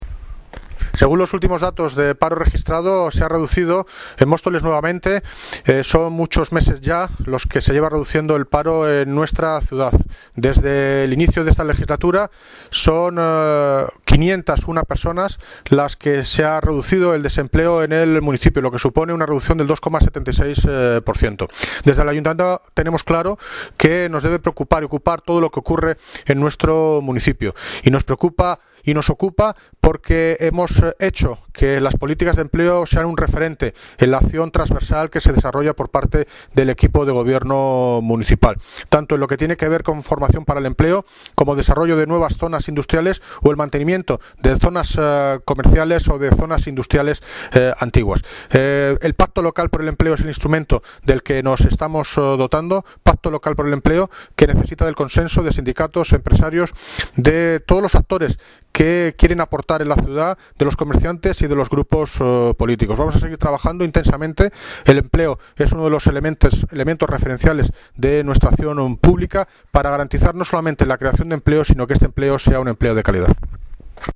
Audio - David Lucas (Alcalde de Móstoles) Sobre disminución del paro